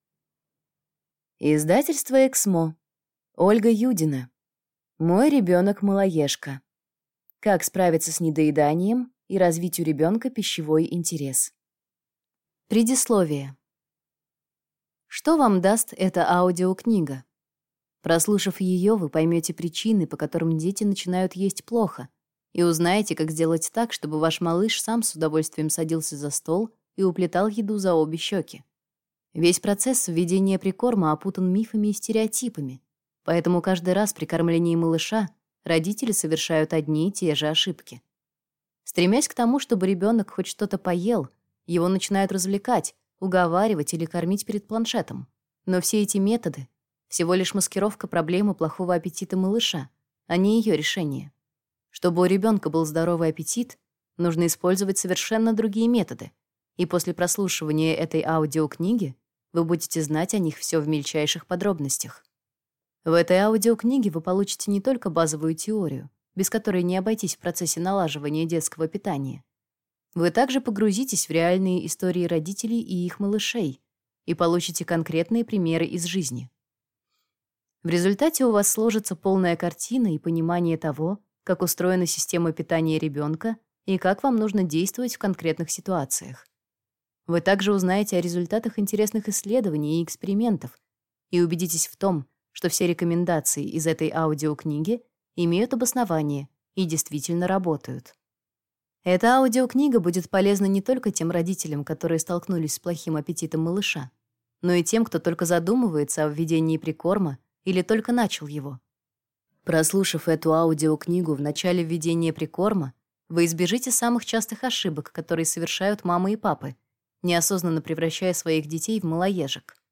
Аудиокнига Мой ребенок – малоежка. Как справиться с недоеданием и развить у ребенка пищевой интерес | Библиотека аудиокниг